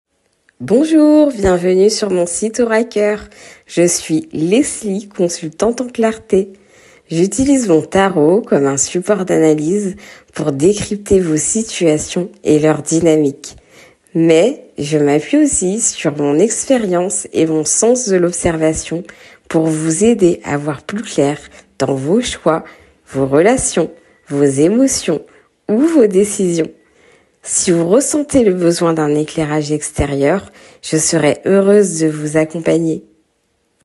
Message de bienvenue
message-bienvenue.mp3